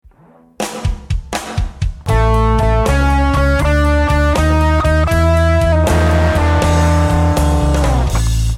Chromatic Leading Chord
In other words a wrong chord creates tension that is resolved by moving to a right chord. Sticking with the key of A for our example the V chord E7 can be preceded by either a F7 or an Eb7.
Blues Turnaround Lick 5